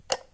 switch35.ogg